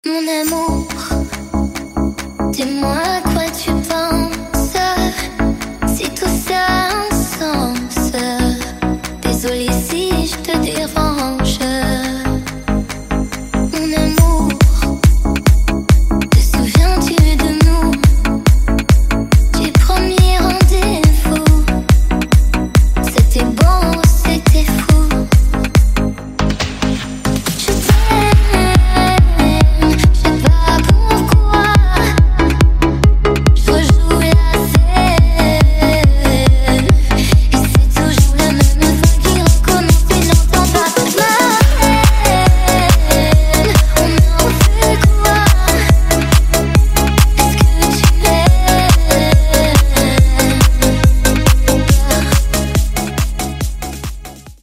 Танцевальные рингтоны
Рингтоны техно
electronic
Deep House